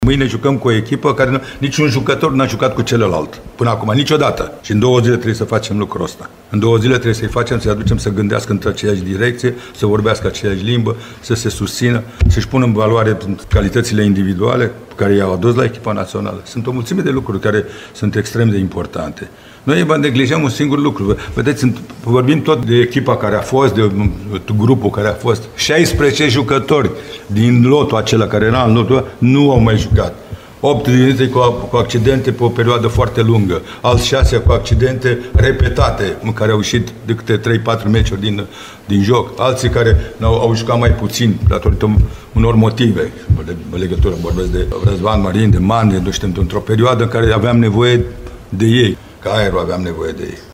Cu o seamă de noutăți în lot, selecționerul Mircea Lucescu declară că a avut prea puțin timp pentru omogenizare: